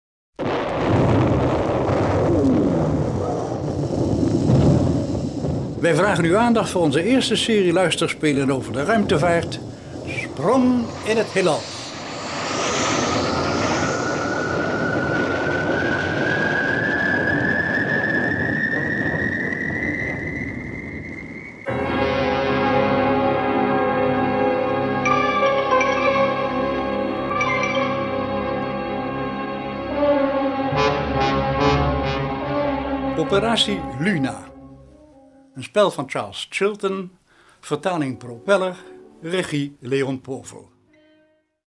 Genre: Sciencefiction